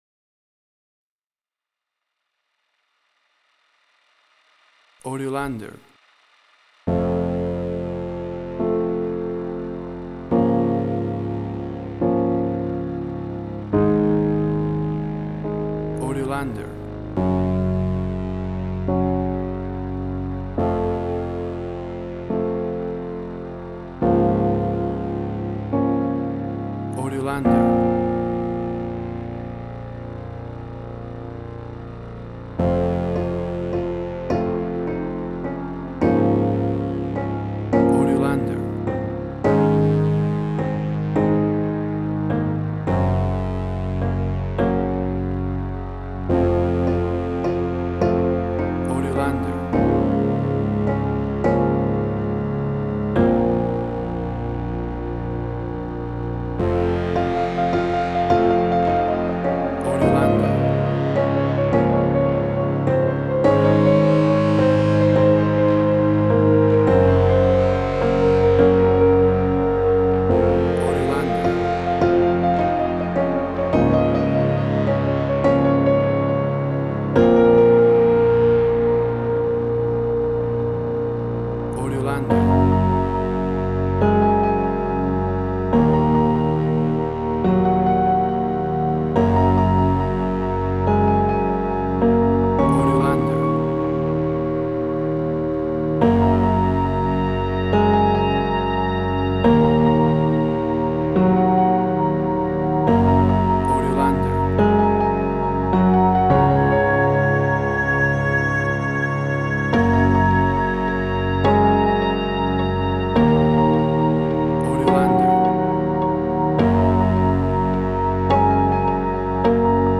Suspense, Drama, Quirky, Emotional.
Tempo (BPM): 100